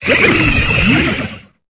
Cri de Prédastérie dans Pokémon Épée et Bouclier.